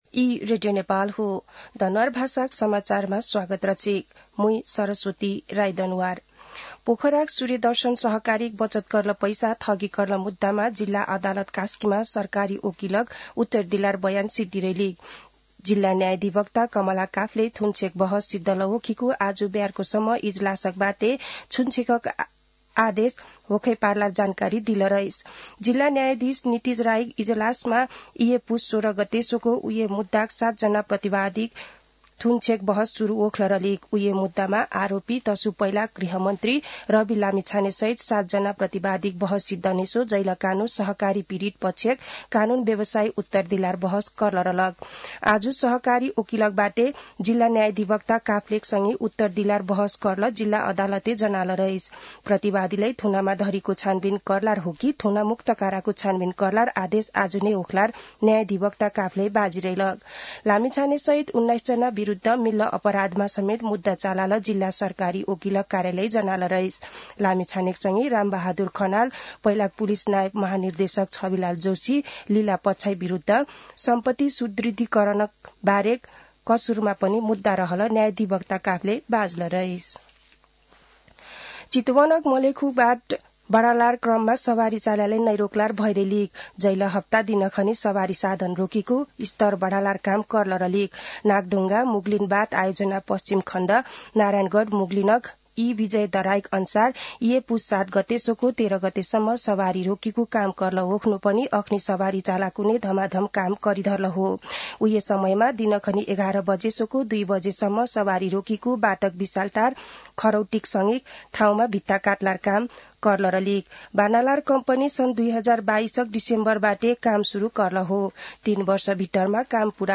दनुवार भाषामा समाचार : २७ पुष , २०८१
Danuwar-news-1-1.mp3